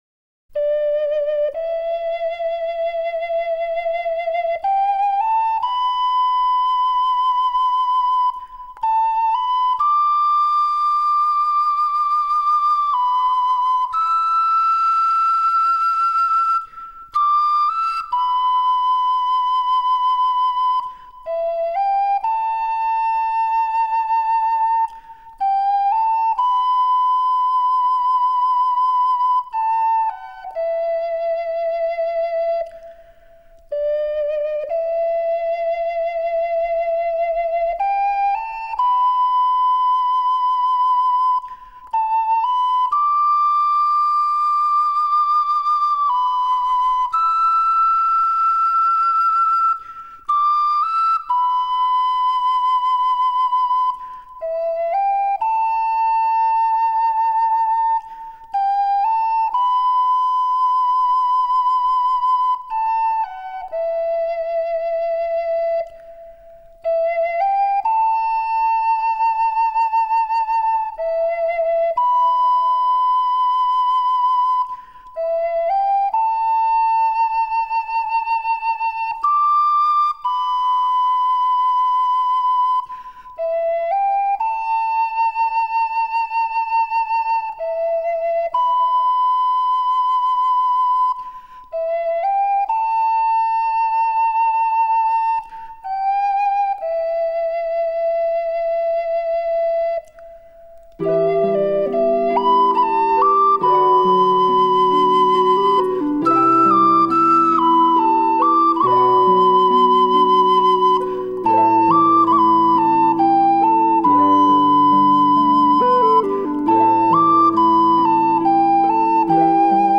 Genre: Newage.